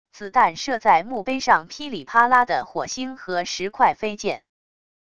子弹射在墓碑上噼里啪啦的火星和石块飞溅wav音频